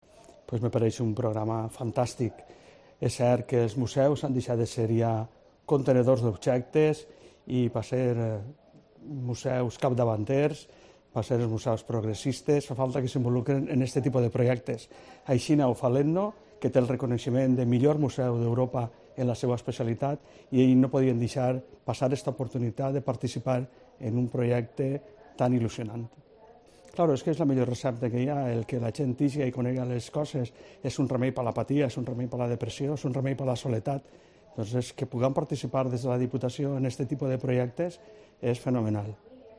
Paco Teruel, diputado provincial en Valencia: Receta Cultura es un programa fantástico